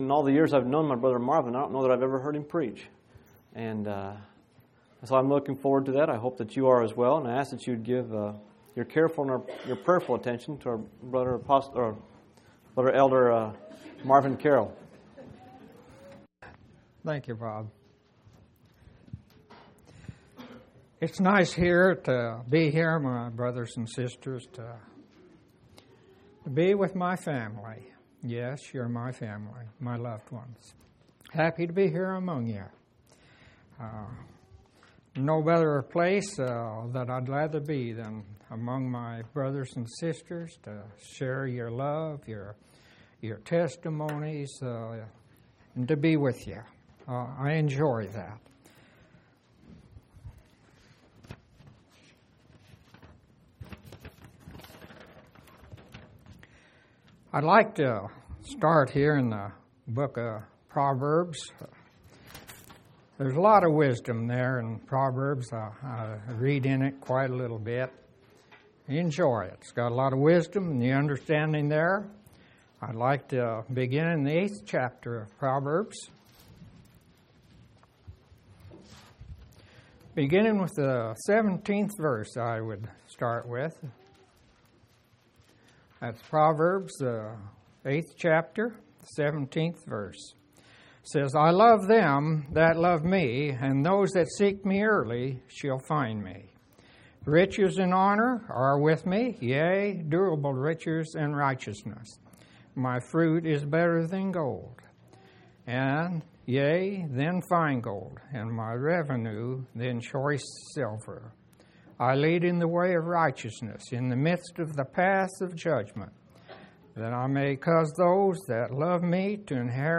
11/25/2005 Location: Phoenix Reunion Event